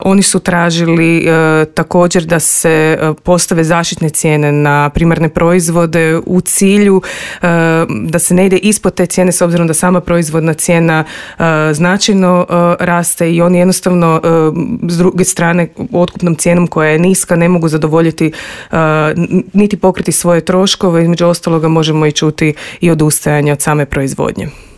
Inflacija i neregulirano tržište postaju sve veći teret, a sektor je ostao i bez resornog ministra. O tome kako spasiti poljoprivrednike, razgovarali smo u Intervjuu Media servisa s voditeljicom Odjela za poljoprivrednu politiku